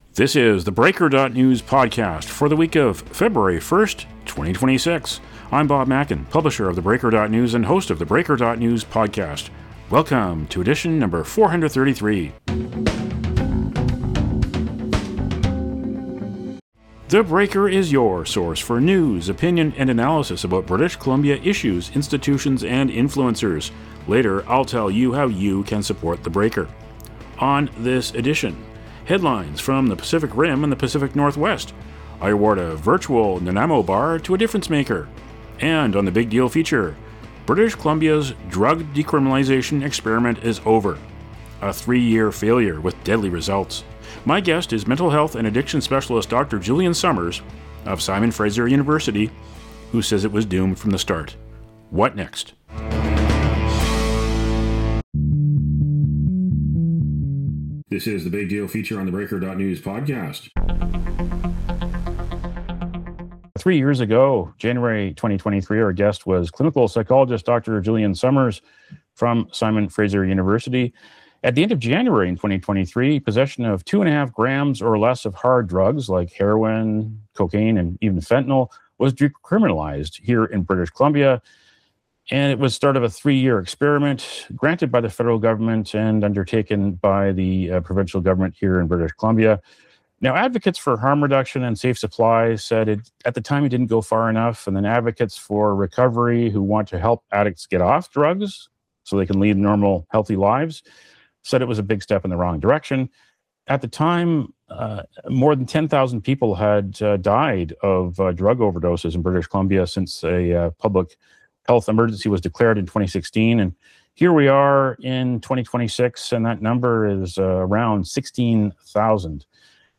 He says it was doomed from the start and offers suggestions on how to proceed. Plus Pacific Rim and Pacific Northwest headlines.